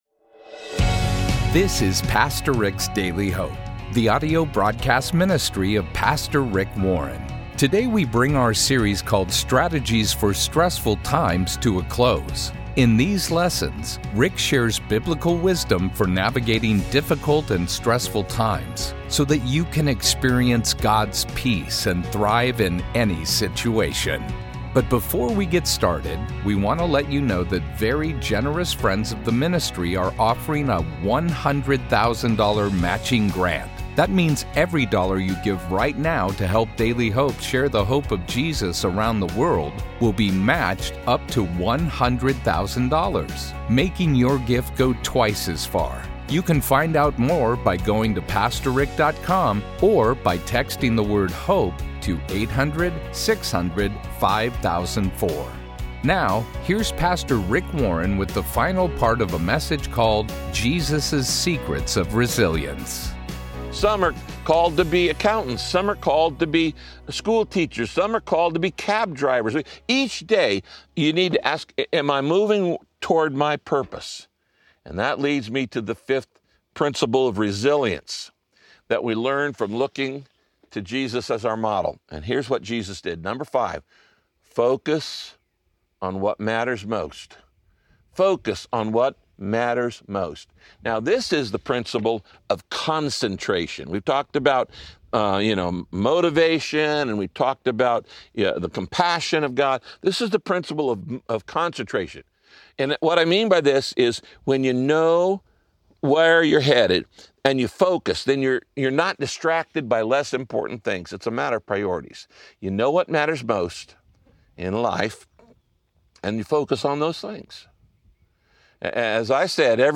If you want to be a resilient person, learn from Pastor Rick as he shares insight on how to develop the habit of spending time with God.
Radio Broadcast Jesus’ Secrets of Resilience – Part 3 Jesus was convinced that, no matter how busy he was, he needed time alone with God to pray.